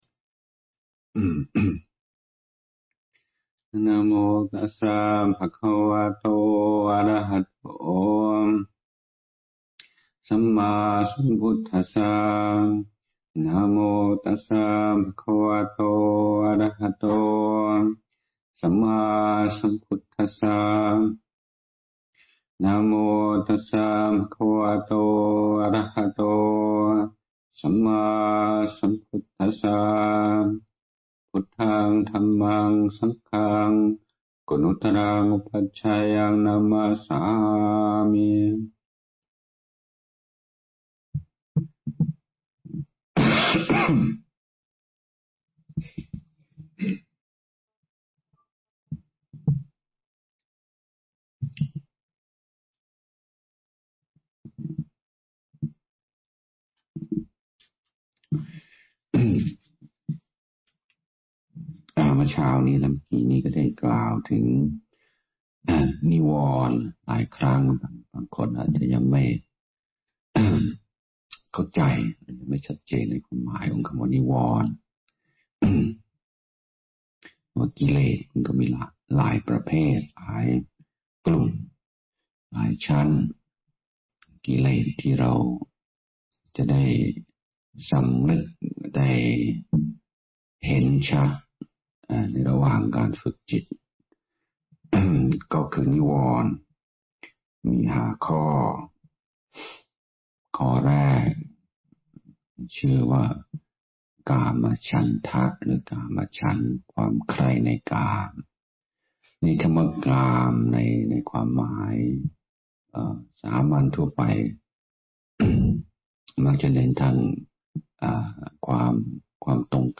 พระพรหมพัชรญาณมุนี (ฌอน ชยสาโร) - ธรรมเทศนาช่วงบ่าย ณ University of the West